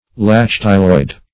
Search Result for " lacertiloid" : The Collaborative International Dictionary of English v.0.48: Lacertiloid \La*cer"ti*loid\, a. [Lacertilia + -oid.]